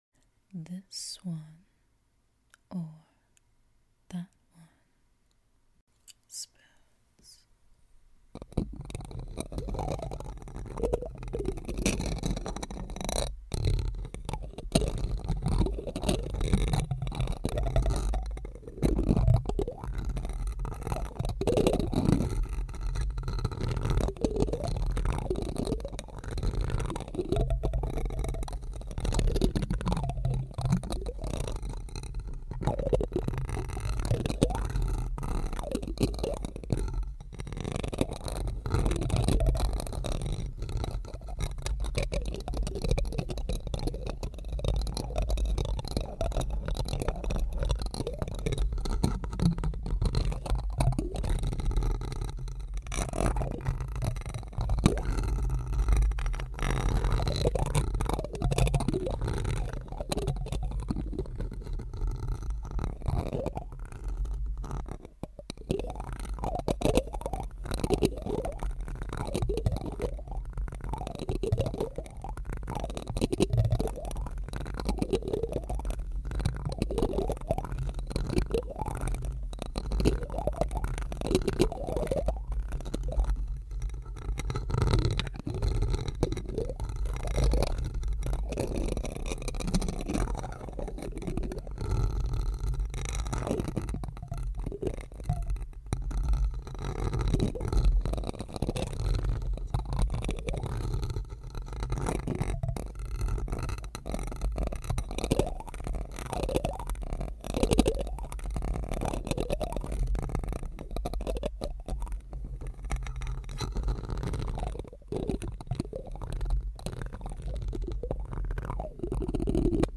(also PS there is still beeping in my apartment complex😞) if you still want to support me today cash app/PayPal in bio<3 #a#asmrsoundsA#ASMRt#tingles Hey, Sorry No Live Today.